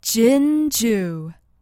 Recorded with an AT2020 mic into an Apogee Duet.
标签： american english female fight speak talk vocal voice